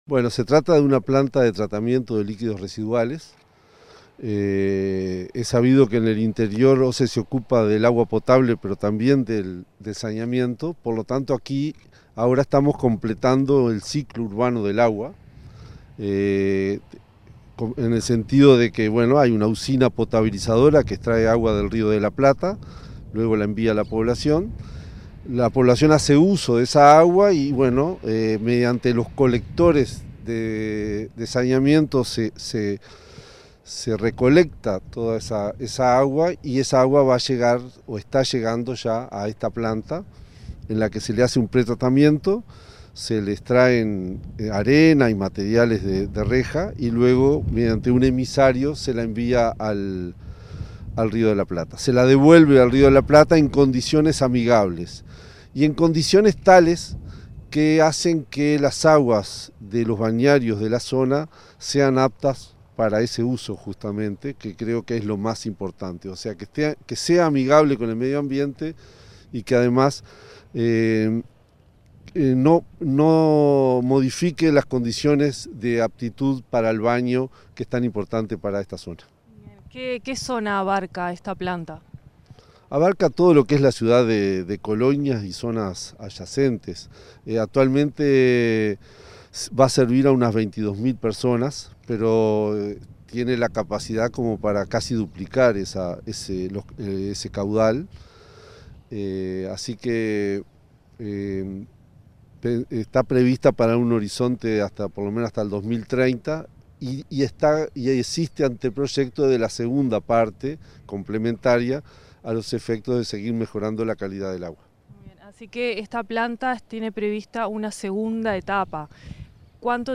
Entrevista al presidente de OSE, Raúl Montero
Entrevista al presidente de OSE, Raúl Montero 10/10/2023 Compartir Facebook X Copiar enlace WhatsApp LinkedIn El presidente de OSE, Raúl Montero, dialogó con Comunicación Presidencial en Colonia, antes de la inauguración de la primera planta de tratamiento de líquidos residuales en la ciudad de Colonia del Sacramento.